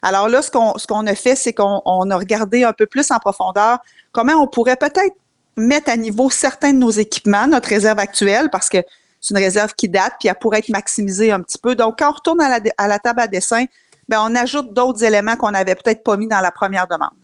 La mairesse, Geneviève Dubois, a résumé la stratégie de la Ville.